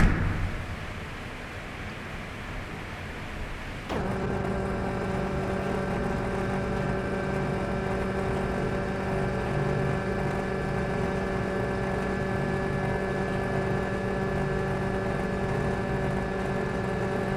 " , PREPARING MIX
15. A big hum.